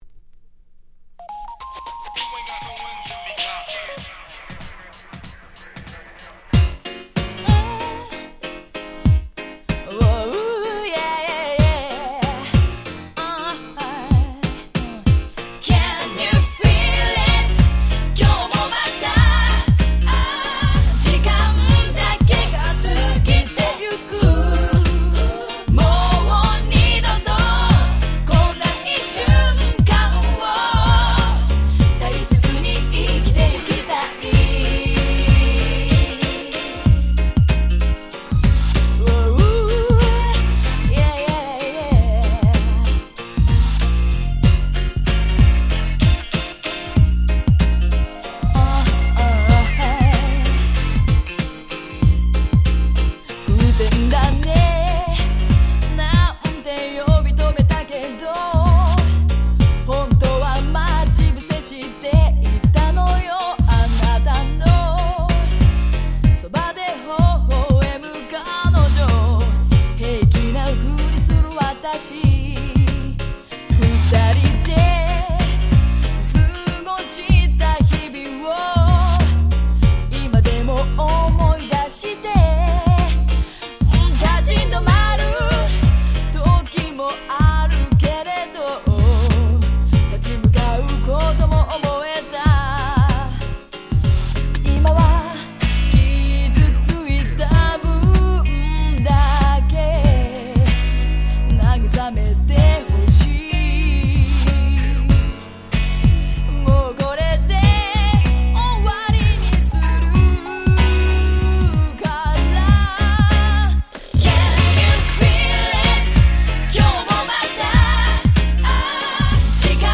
99' Nice Japanese R&B !!